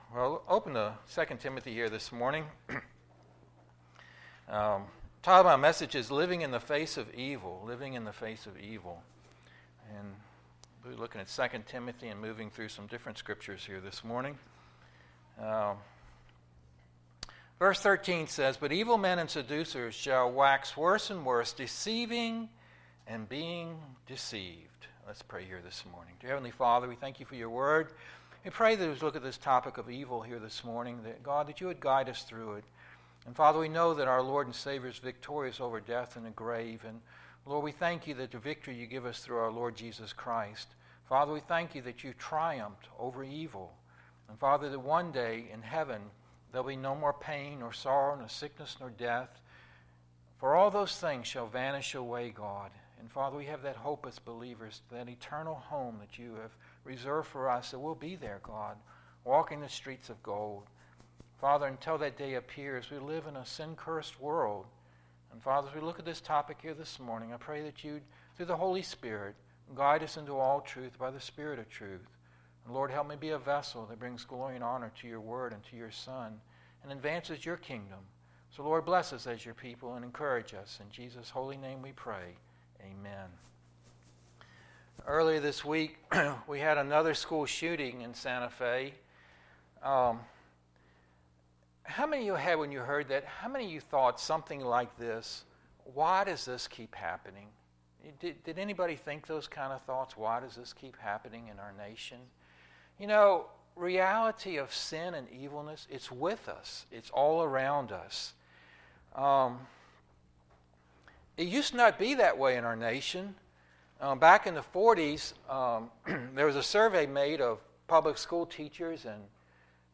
Bible Text: 2 Timothy 3:1-17 | Preacher